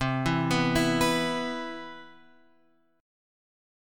C Major 7th